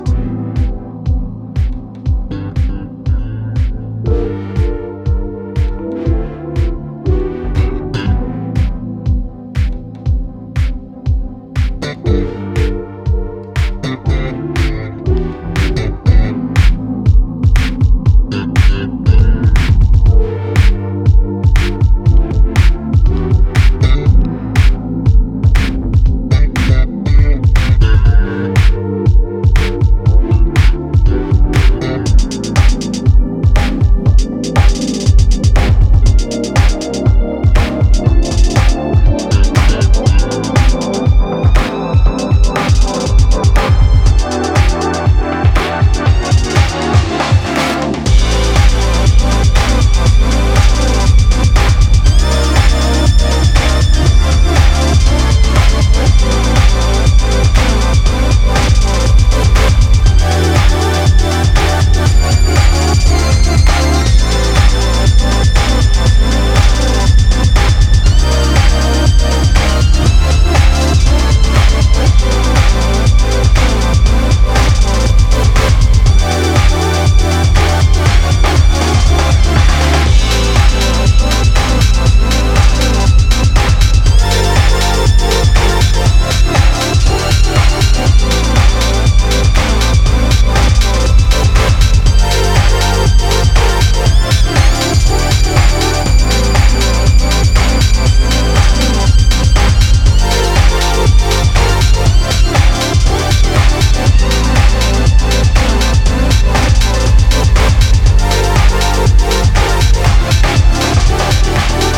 he drives his synth and crushed house sound in the future.